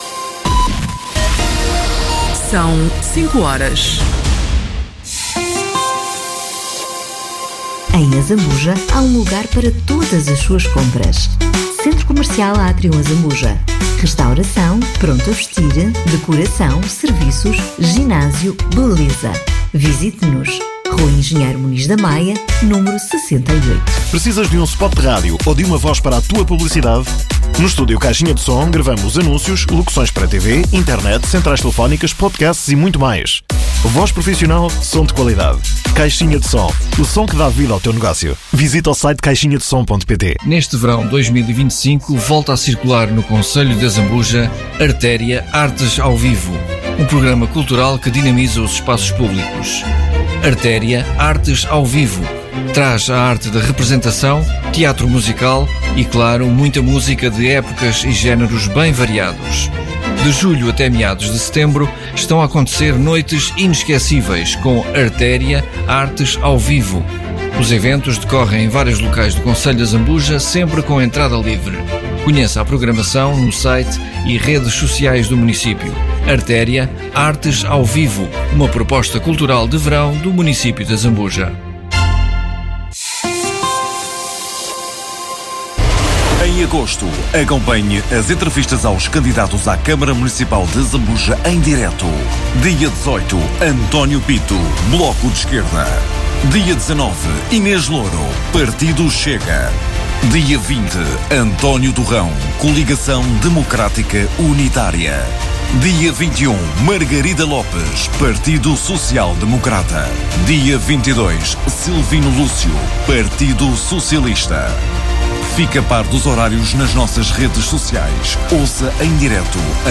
Autárquicas 2025 - Azambuja - Entrevista